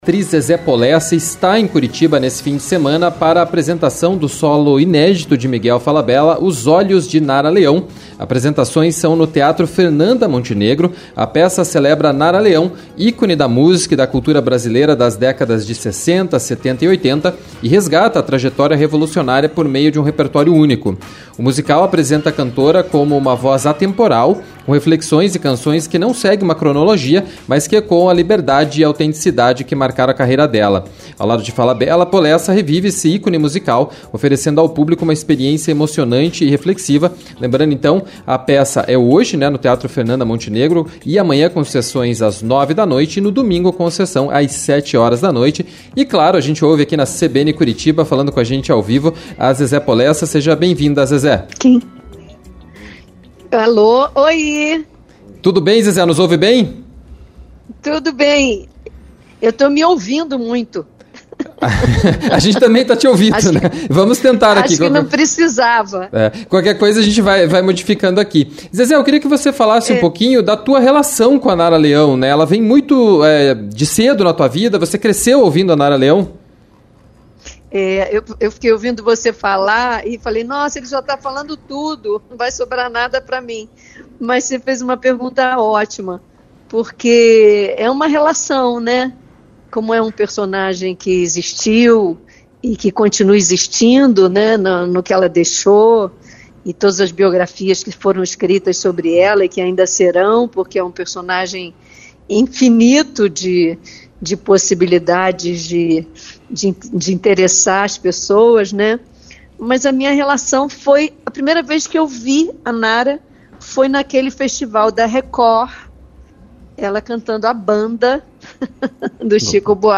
Confira a entrevista completa com a atriz Zezé Polessa.